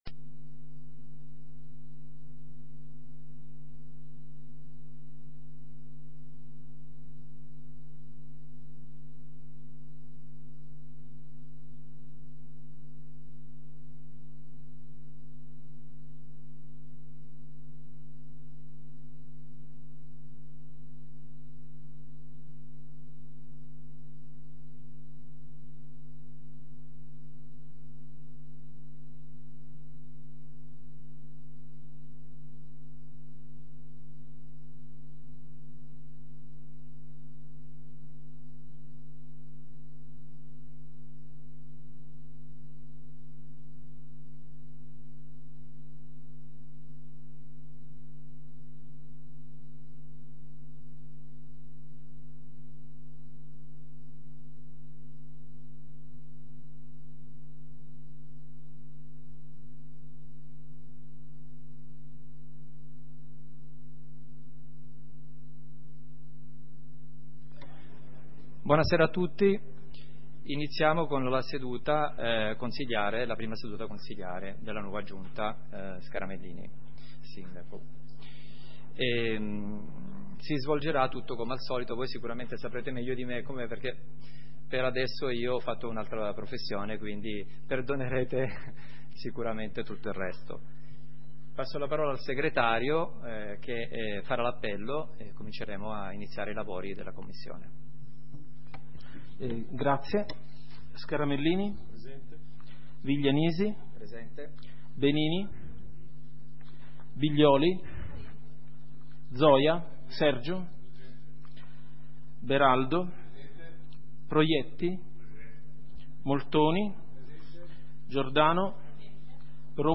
Seduta consiglio comunale del 31 maggio 2023 - Comune di Sondrio